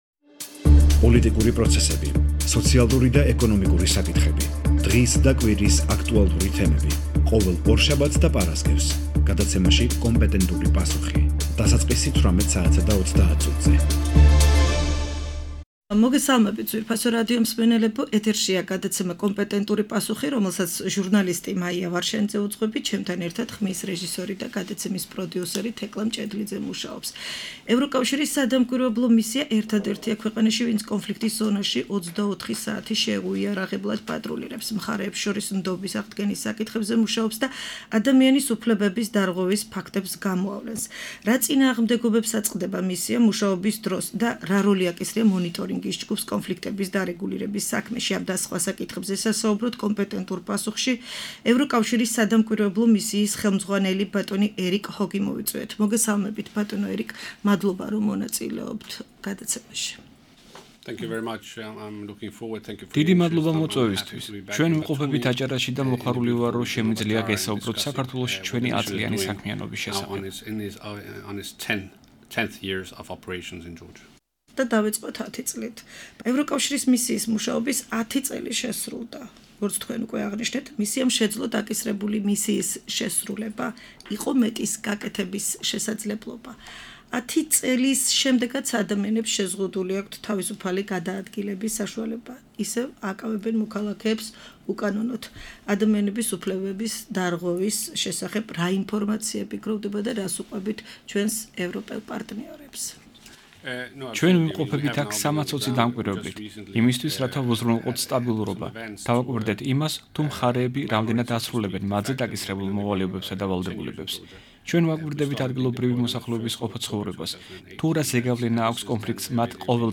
რა წინააღმდეგობებს აწყდება ევროკავშირის მისია მუშაობის დროს და რა როლი აკისრია მონოტორინგის ჯგუფს მხარეთა ურთიერთობების დარეგულირების საქმეში?-ამ და სხვა საკითხებზე ევროკავშირის სადამკვირვებლო მისიის ხელმძღვანელი ერიკ ჰოგი საუბრობს.